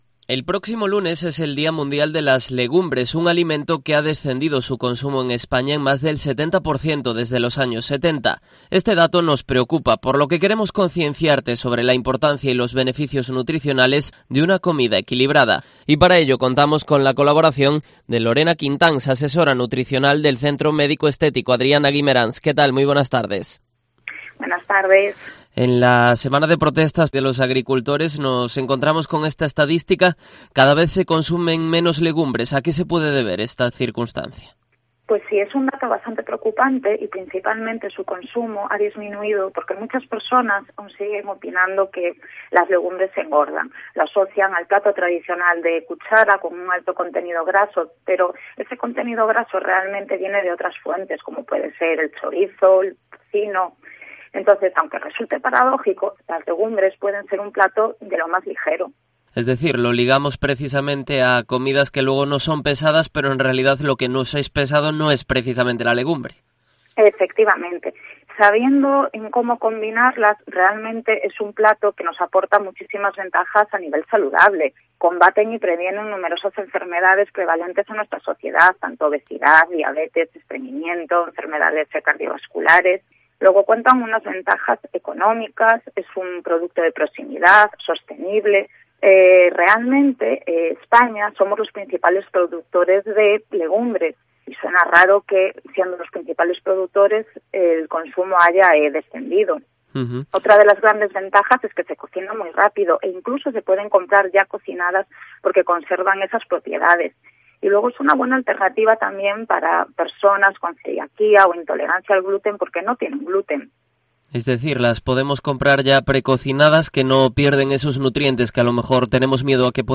Entrevista con la asesora nutricional